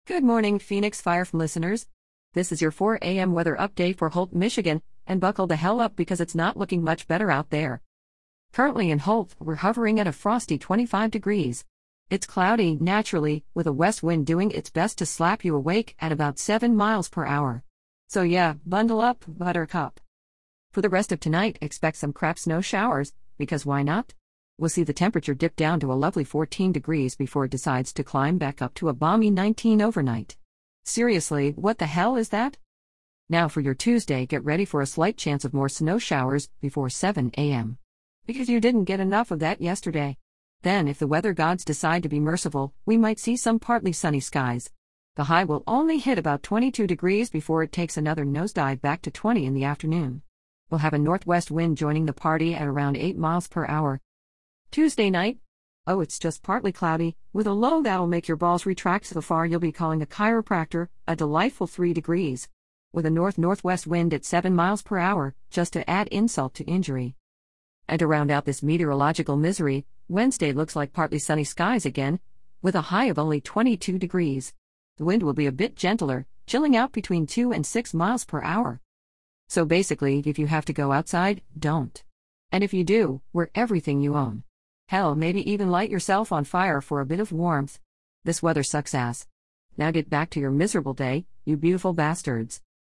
As some of you might know, I have the weather announced a few minutes after the top of the hour. this weather is genorated by I believe Gemini, and I have it set to be rather sarcastic, and I told it that it is for an adult radio station, so swairing is aloud. Well here is what it came up with for the 4:00 hour this morning.